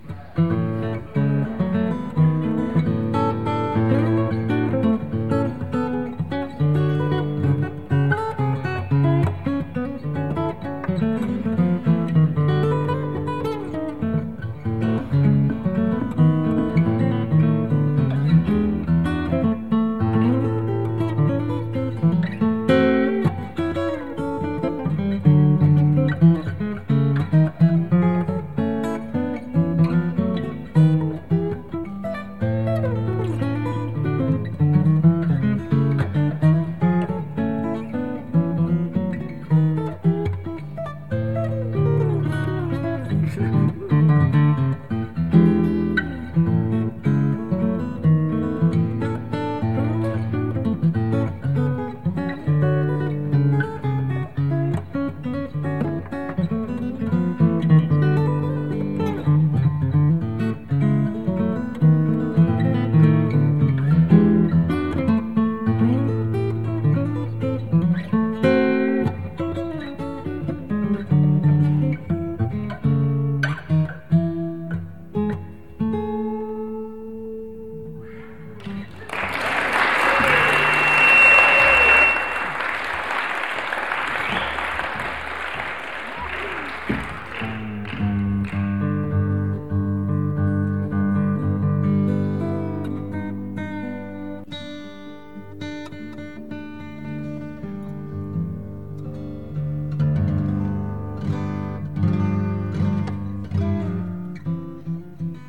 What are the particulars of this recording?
Live at Kelly Hall, Antioch College, November 29, 1972